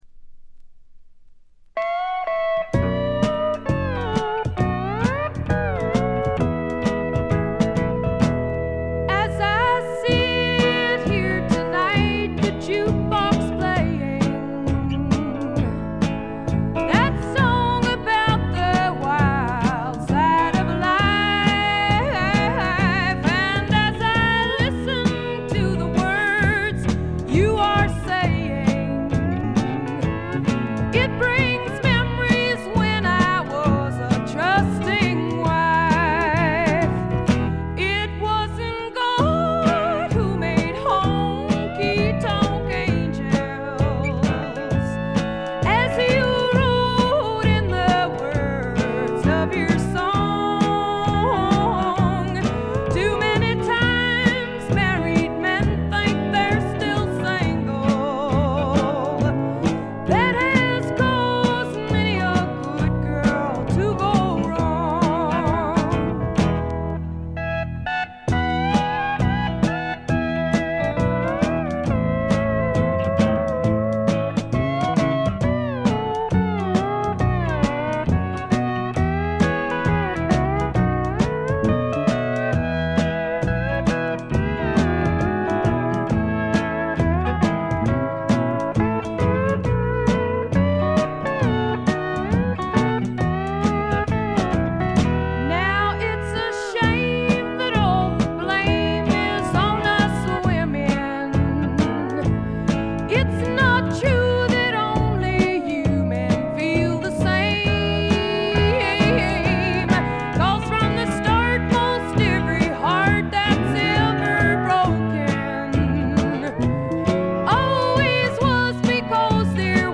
部分試聴ですがほとんどノイズ感無し。
唯一タイトル・ナンバーだけが浮きまくりのカントリー・アレンジでキョトンとしてしまいますね。
試聴曲は現品からの取り込み音源です。
Side B Recorded at The Record Plant, N.Y.C.